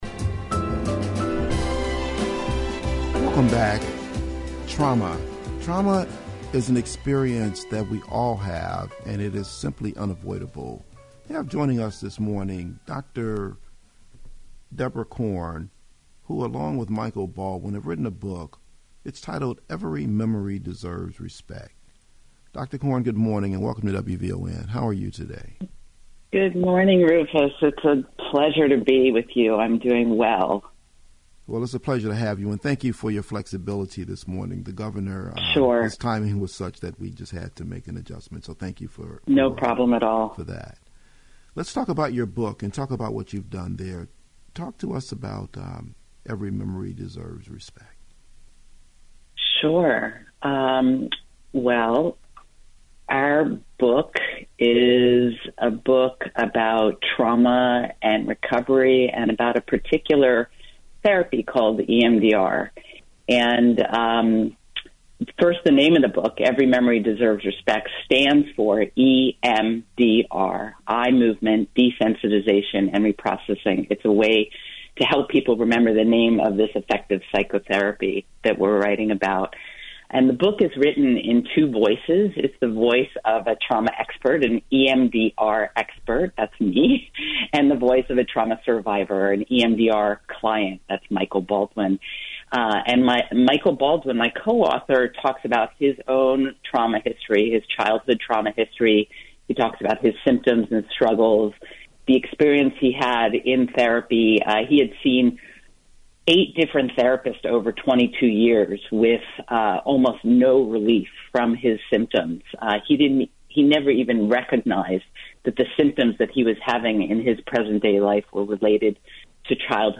WVON Radio Chicago Podcast (Interview - Audio) September 13